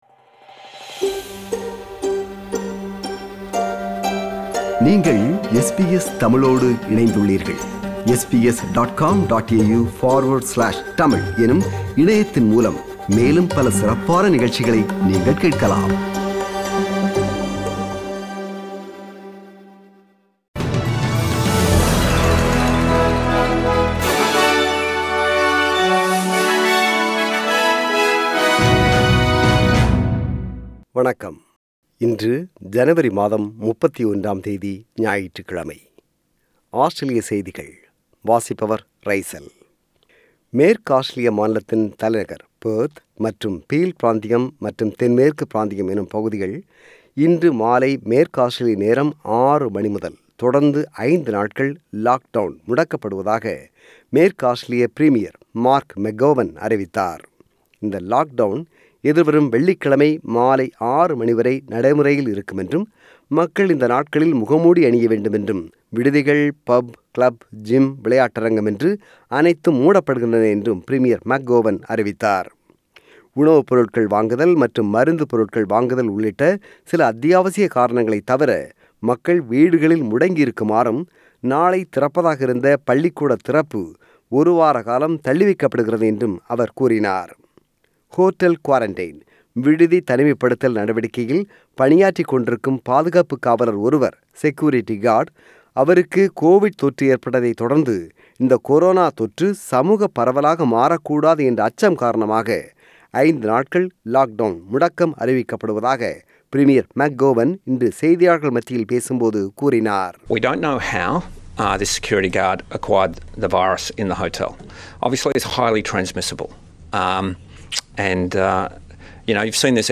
Australian News: 31 January 2021 – Sunday